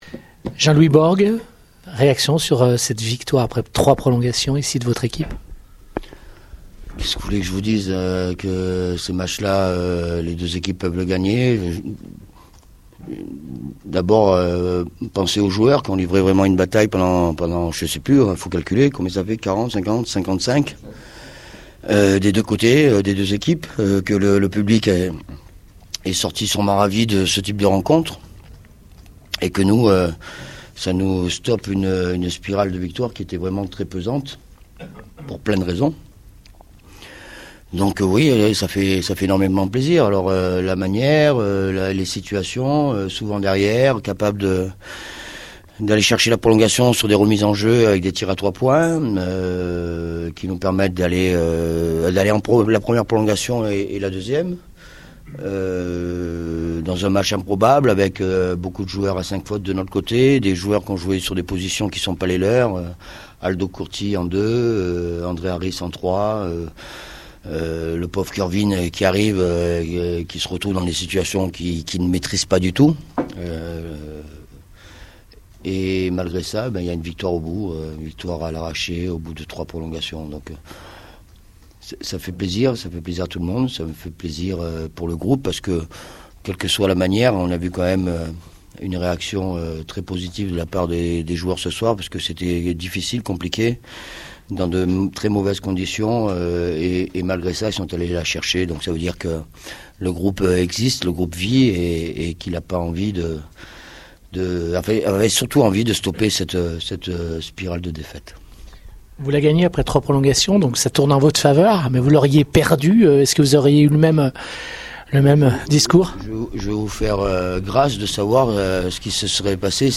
les interviews d’après-match pour Radio Scoop